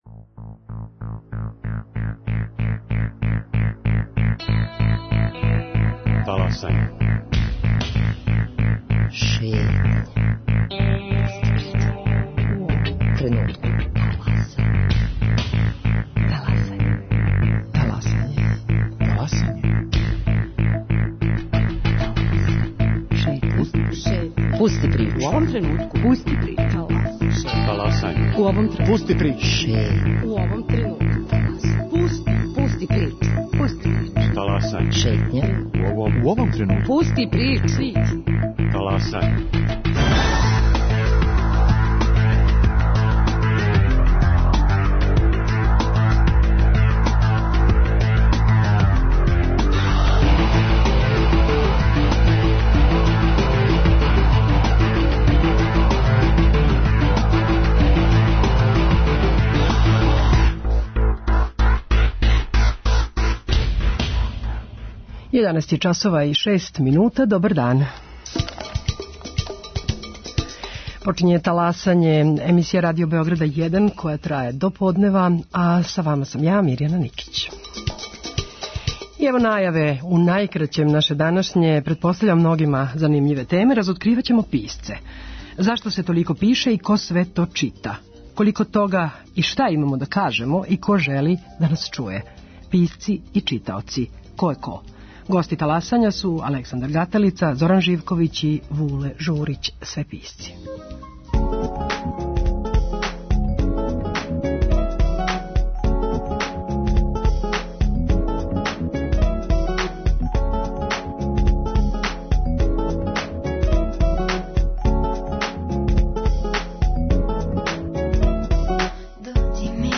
Гости Таласања су писци